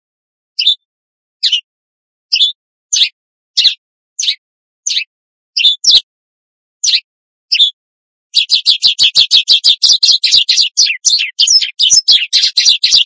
Tiếng chim Gọi Nhau
Đây là bản thu tổng hợp những tiếng kêu giao tiếp giữa các cặp chim hoặc bầy đàn trong tự nhiên. Những âm thanh này thường mang tính chất ngắn, vang và lặp lại theo nhịp điệu, tạo nên một không gian âm thanh xôn xao, ấm áp và tràn đầy sự gắn kết của núi rừng buổi sớm mai.
• Âm thanh đa tầng: Bản thu thường bao gồm tiếng của nhiều loài chim nhỏ như chim Sẻ, Khuyên, Chào Mào gọi nhau, tạo nên một lớp nền (ambience) cực kỳ dày dặn và chân thực.
• Chất lượng bản thu: File đã được lọc bỏ các tạp âm như tiếng gió rít hay tiếng máy móc, chỉ giữ lại tiếng chim trong trẻo trên nền âm thanh tĩnh lặng của thiên nhiên.